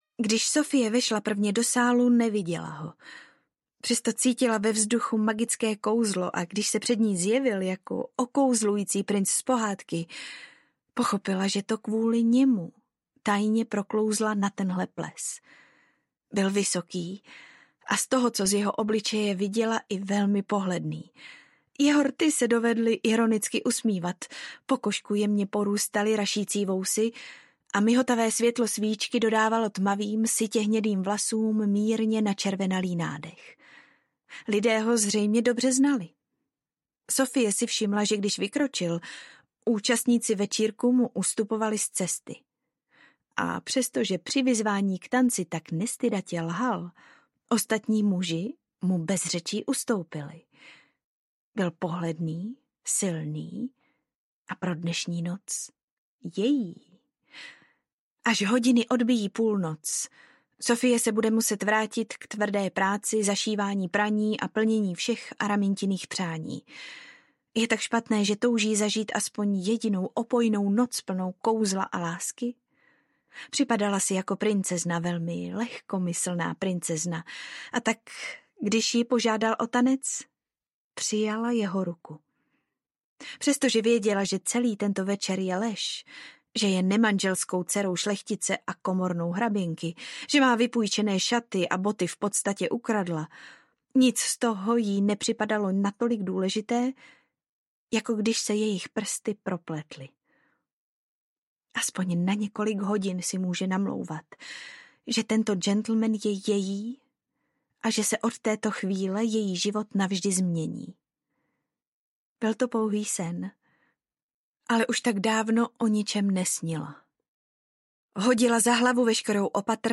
Audiokniha Nevhodný návrh obsahuje třetí díl romantické série Bridgertonovi.